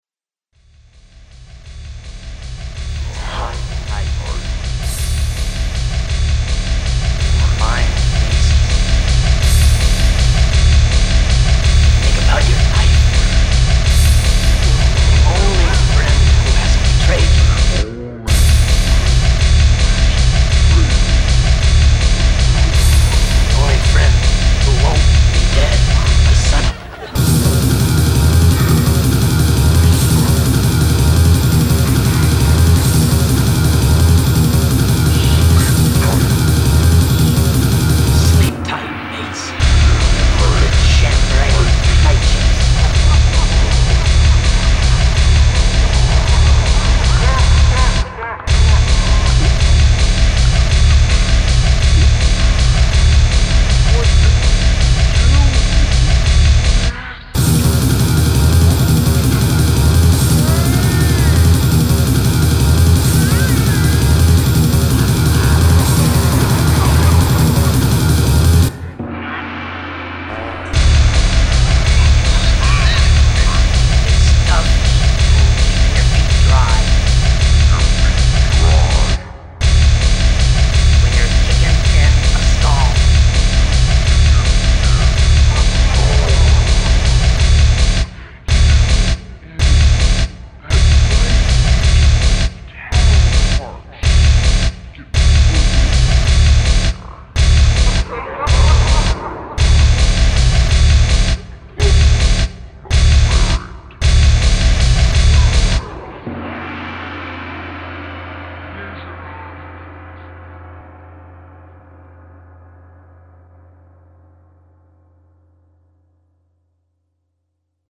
Heavy Rock Parody
Seinfeld V.O.